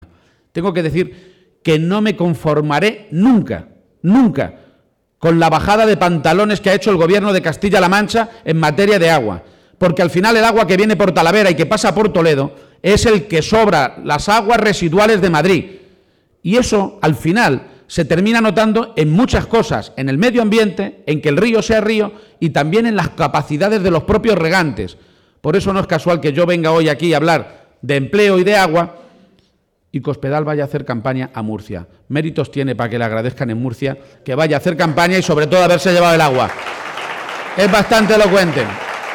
García-Page encaraba, pues, este último fin de semana de campaña con un acto público en Oropesa en el que sostenía que la prioridad en esta localidad, como en Talavera de la Reina, la ciudad más importante de esta comarca, la segunda en población y la primera por tasa de paro de Castilla-La Mancha, es el empleo, y decía que esta medida de dedicar el dos por ciento del presupuesto neto de la Junta a este problema «resume seguramente todas las medidas que hemos ido presentando para luchar contra el paro».